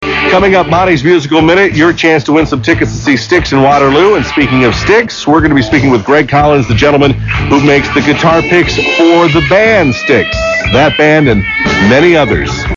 97X pre - post interview discussion 5.mp3